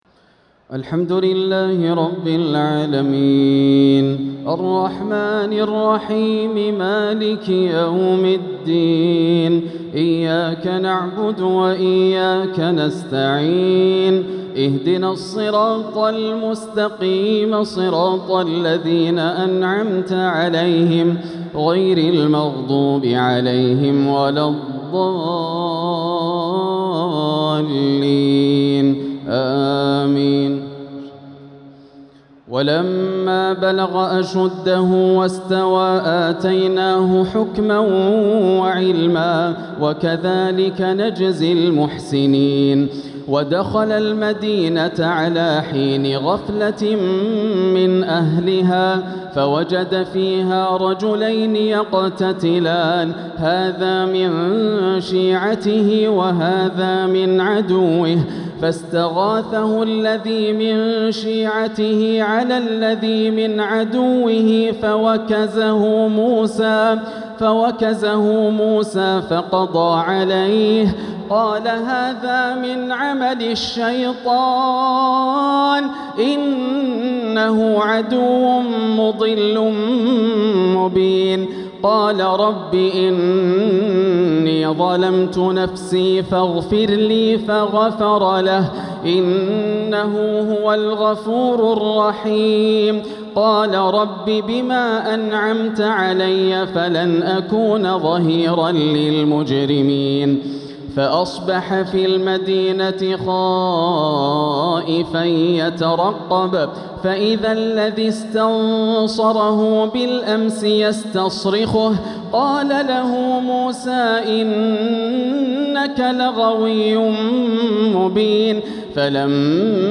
تهجد ليلة 23 رمضان 1447هـ من سورتي القصص (14-88) و العنكبوت (1-27) > الليالي الكاملة > رمضان 1447 هـ > التراويح - تلاوات ياسر الدوسري